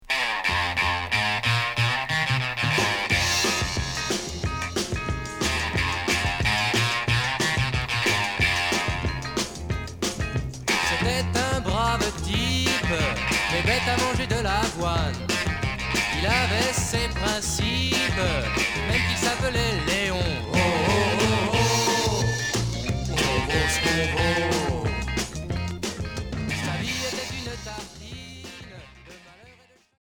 Chanteur 60's